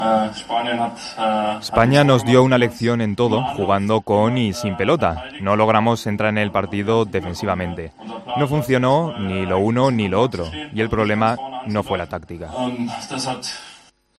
AUDIO: El jugador alemán del Real Madrid habló tras la dura derrota ante España.